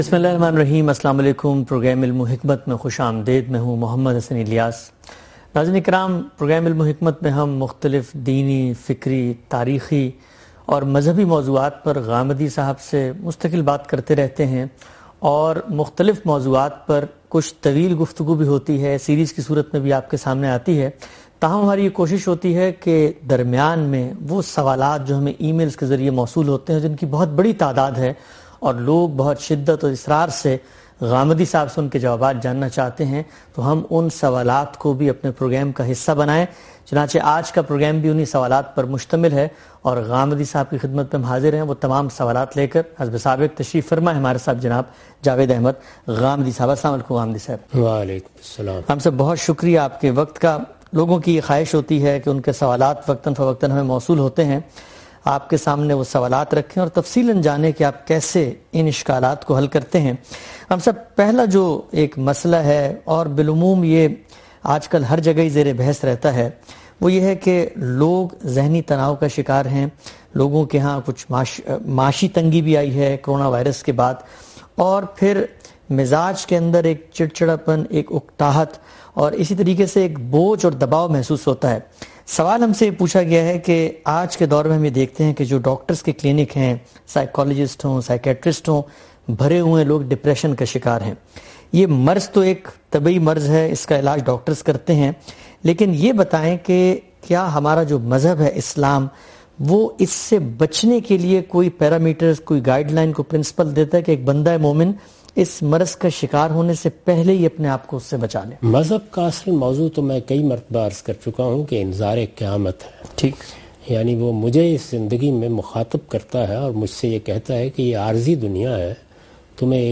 In this program Javed Ahmad Ghamidi answers the questions of different topics in program "Ilm-o-Hikmat".